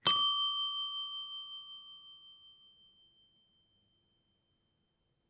Bell.
bell bells ding dong ring sound effect free sound royalty free Sound Effects